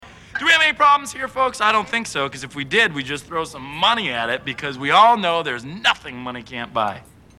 Category: Movies   Right: Personal